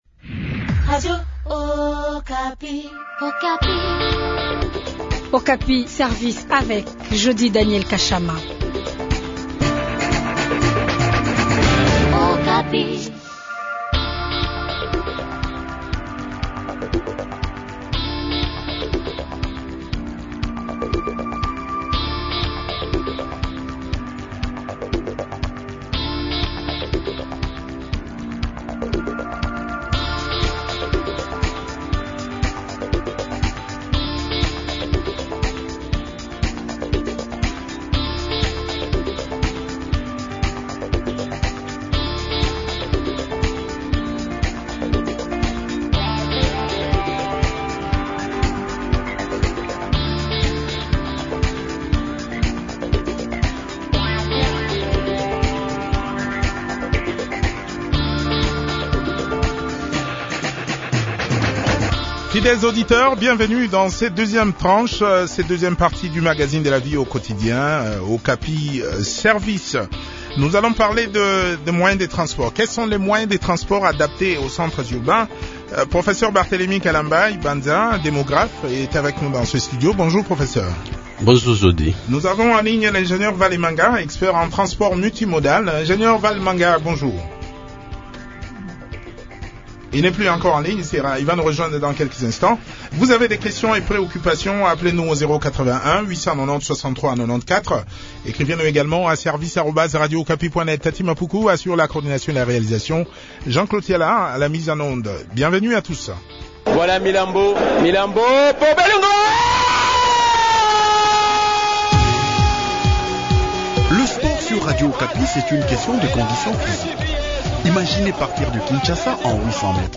expert en transport multimodal.